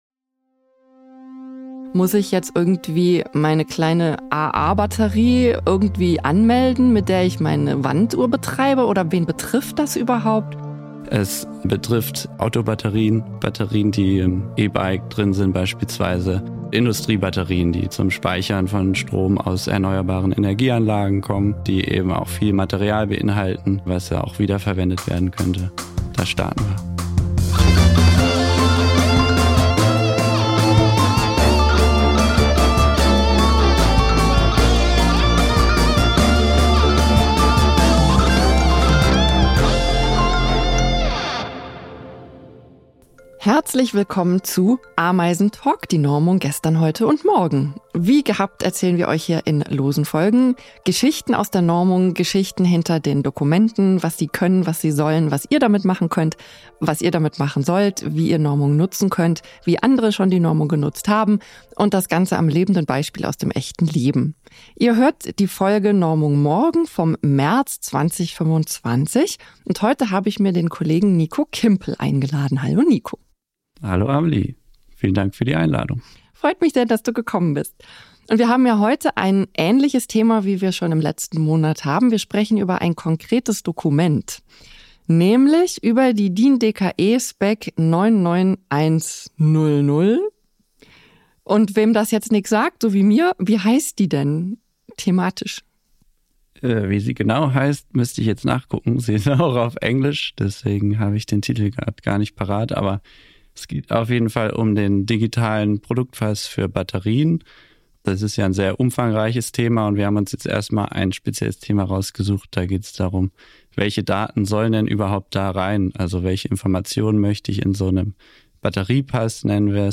In dieser Interviewreihe stellen wir euch echte DINies vor - Mitarbeitende von DIN, die spannende Normungsgeschichten erzählen.